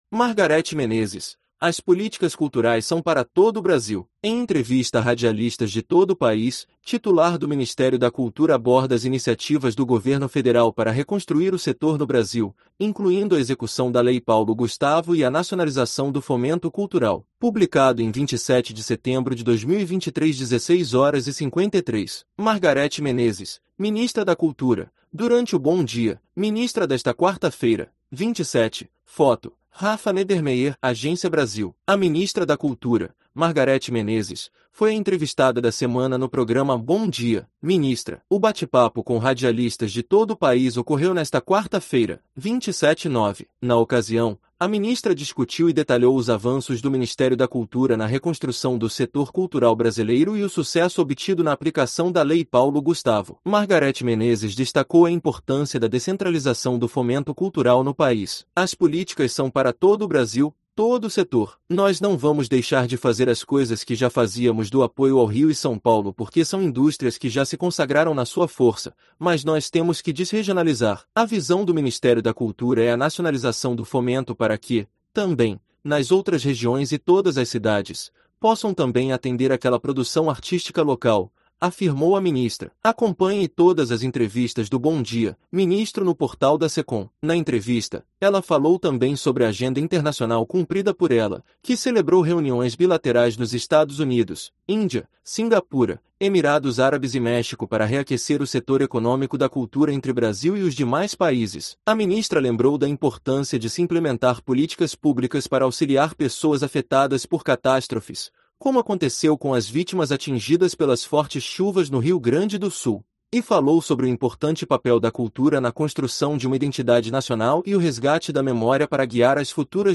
Em entrevista a radialistas de todo o país, titular do Ministério da Cultura aborda as iniciativas do Governo Federal para reconstruir o setor no Brasil, incluindo a execução da Lei Paulo Gustavo e a nacionalização do fomento cultural